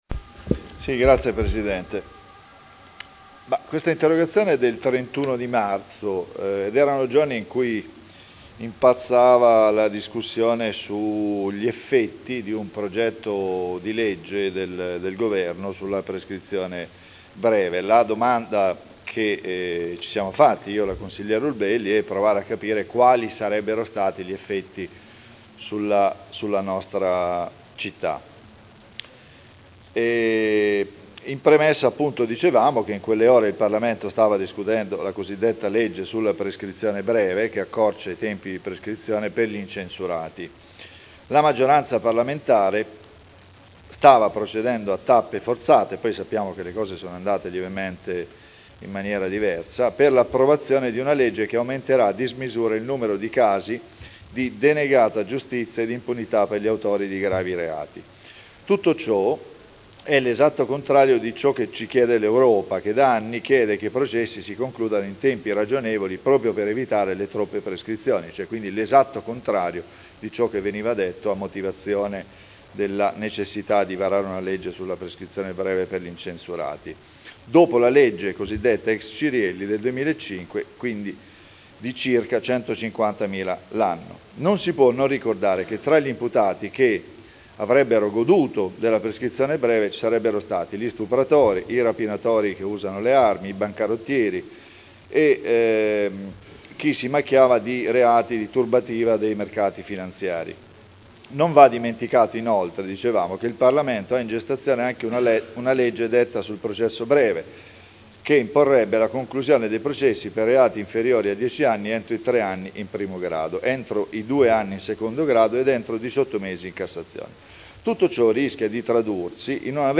Seduta del 21/07/2011. Interrogazione dei consiglieri Trande e Urbelli (P.D.) avente per oggetto: “Legge sulla “Prescrizione breve”: quali conseguenze sono ipotizzabili sulla sicurezza del nostro territorio?” – Primo firmatario consigliere Trande (presentata l’1 aprile 2011 - in trattazione il 21.7.2011)
Audio Consiglio Comunale